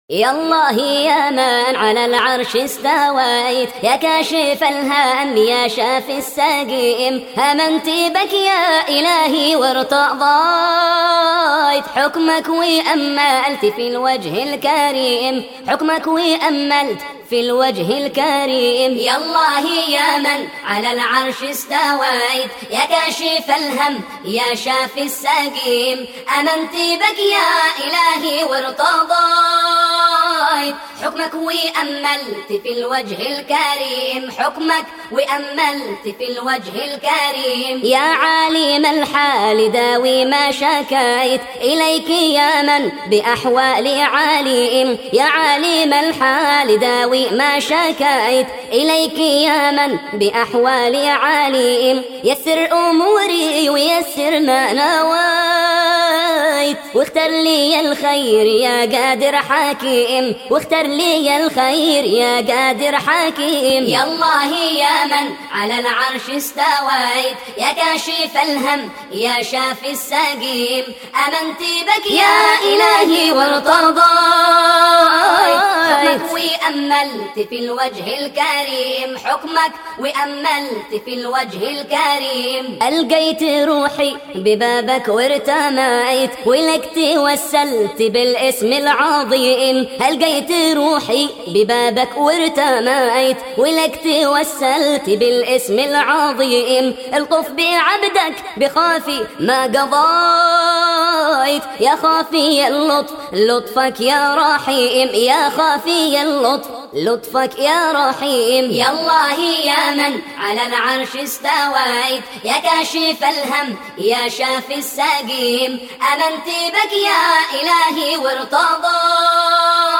انشاد